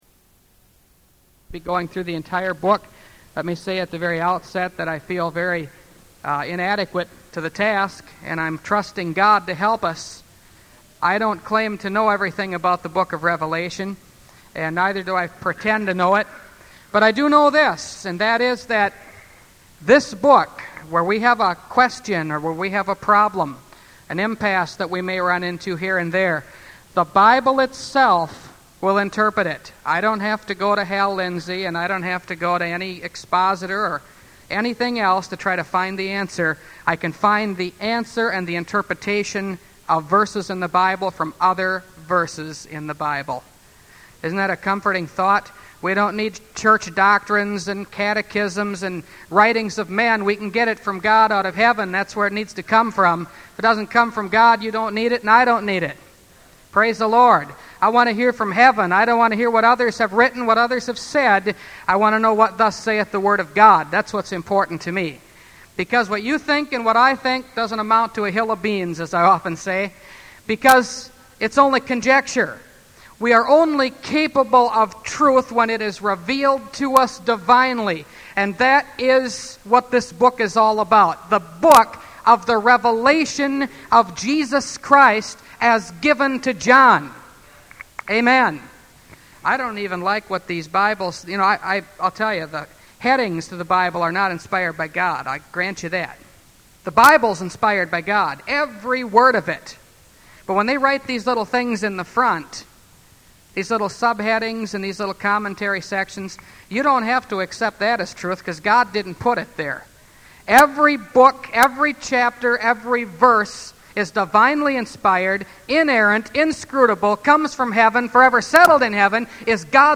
Revelation Series – Part 1 – Last Trumpet Ministries – Truth Tabernacle – Sermon Library